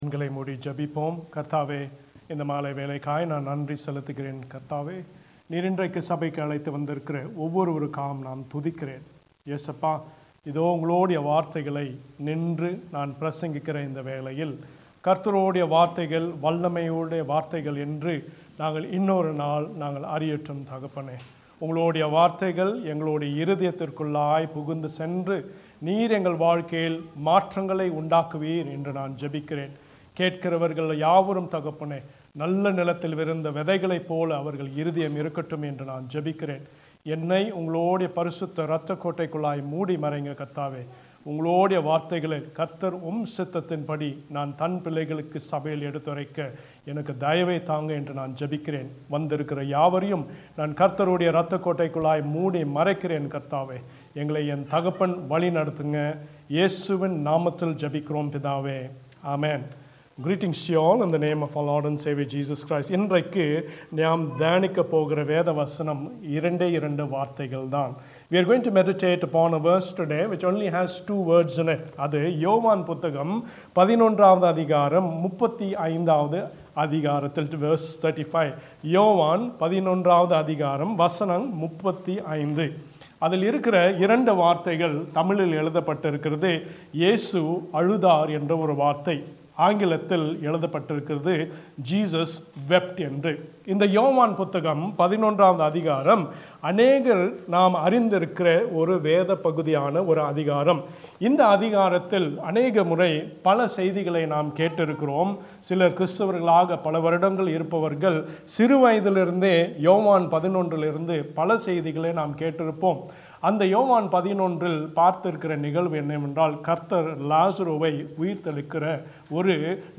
Sermon-4May2025.mp3